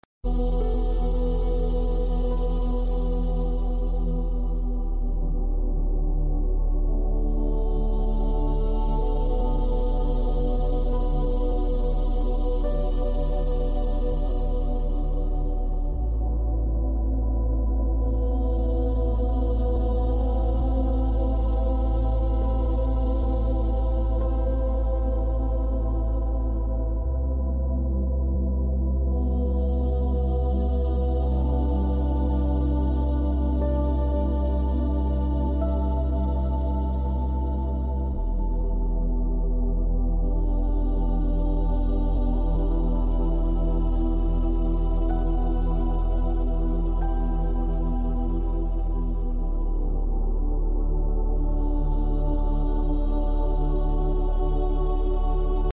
432 Hz + 528 Hz sound effects free download